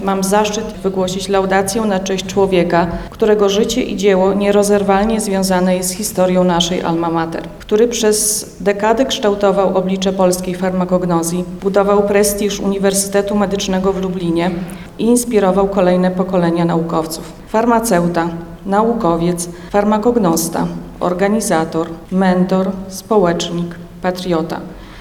Uniwersytet Medyczny w Lublinie rozpoczął dziś dwudniowe obchody jubileuszu 80-lecia Wydziału Farmaceutycznego oraz 30-lecia nauczania Analityki Medycznej.